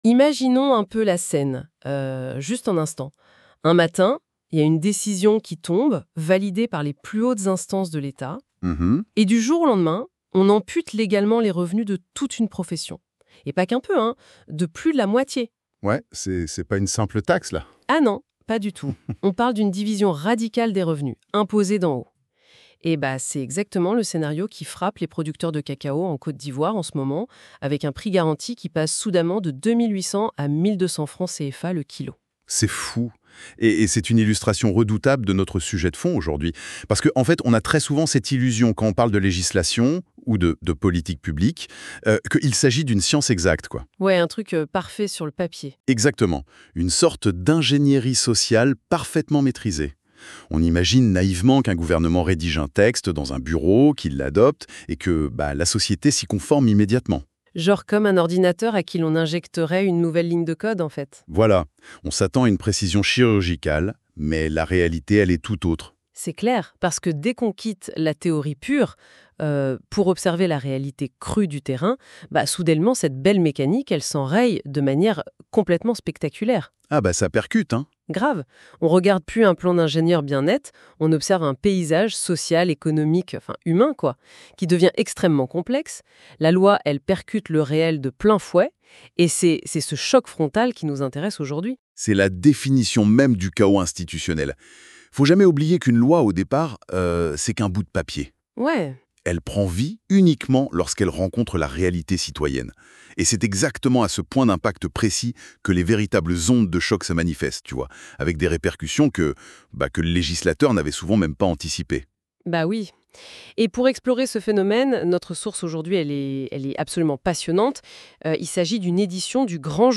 LE GRAND JOURNAL - RADIOTAMTAM AFRICA